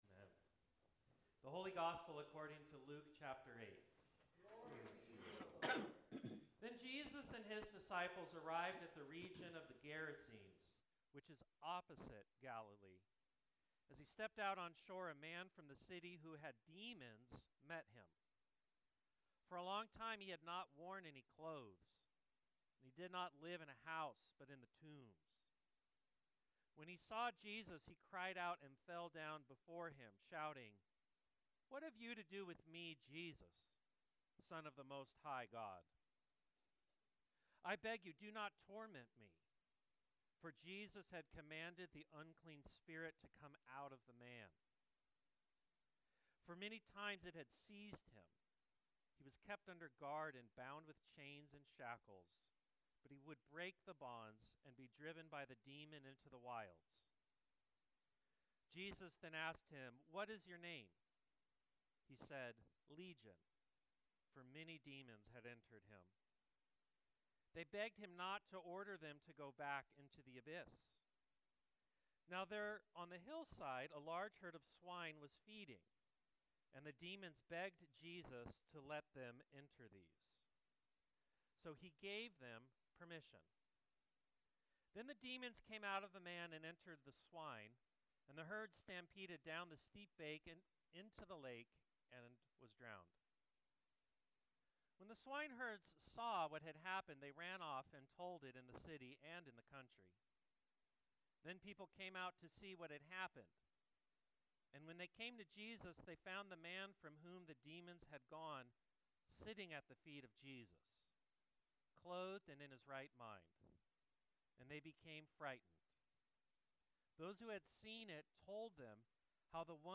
Sermon 06.22.25